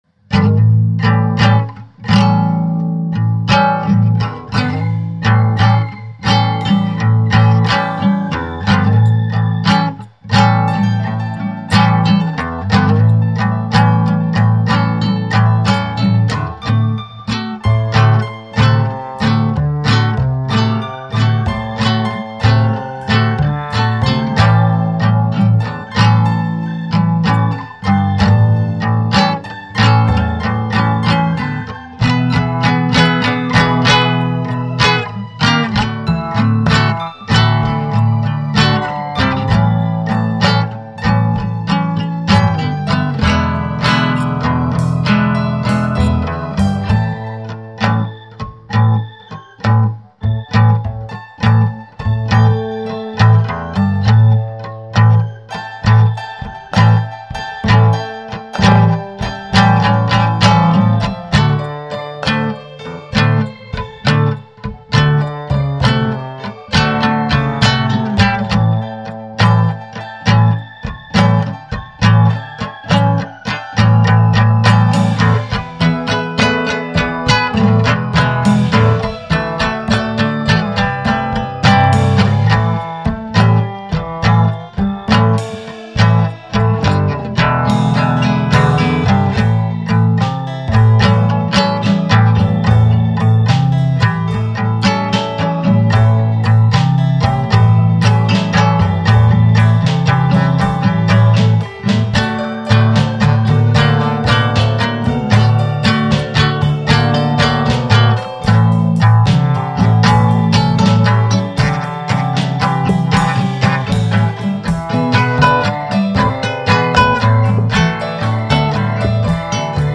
Free Brazilian Home Made Indie Music
Country
Folk